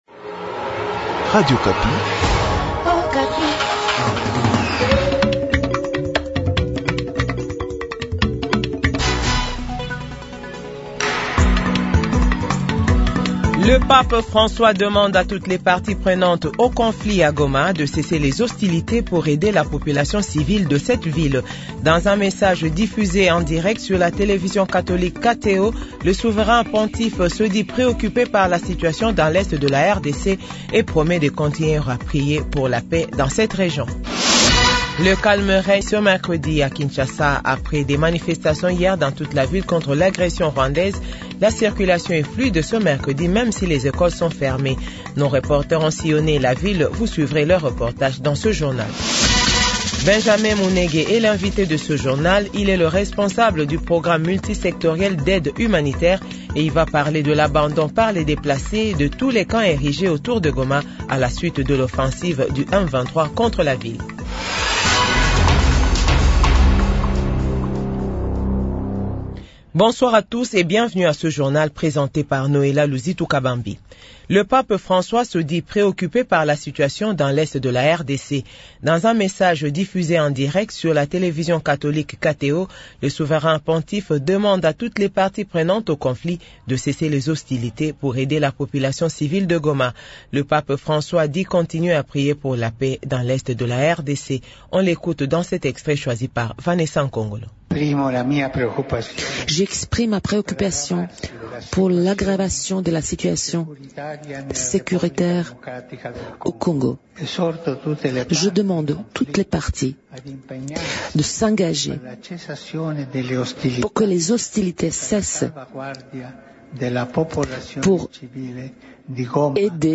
Journal 18h00